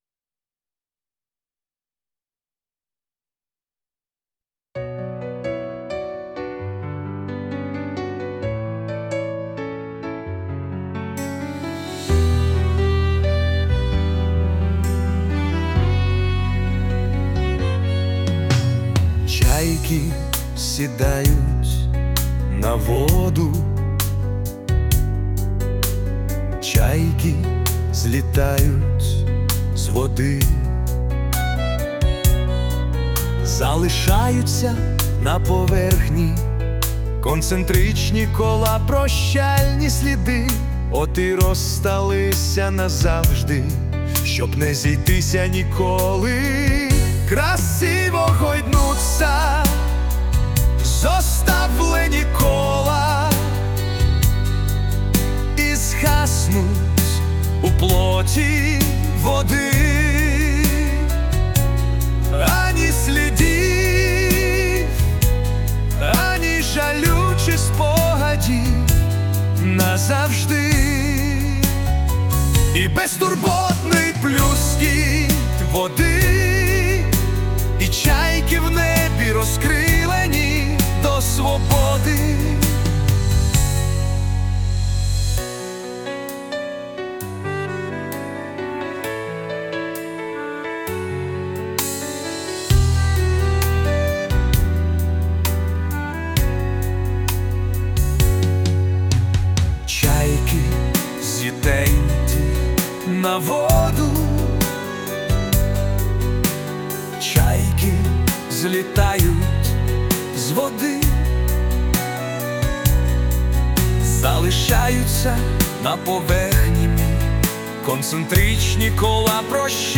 музику і виконання згенерував ШІ